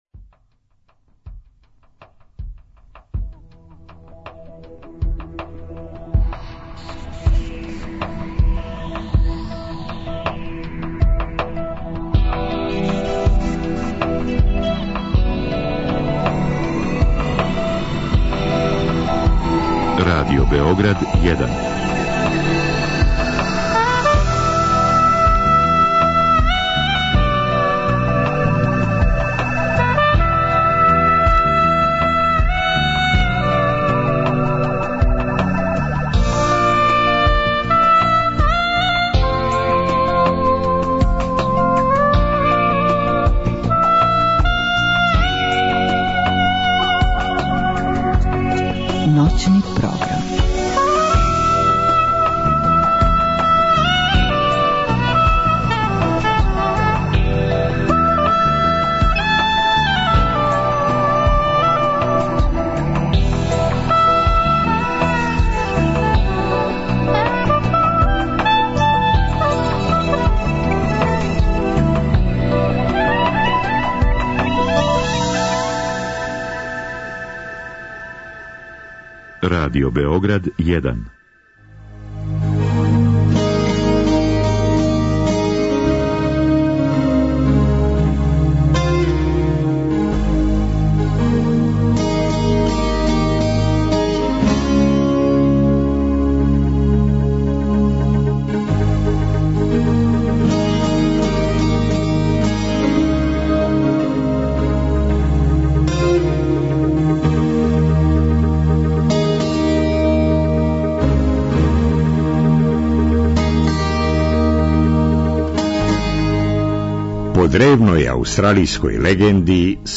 Облик и звук тог инструмента као да припадају некој другој димензији, или бар свету научне фантастике.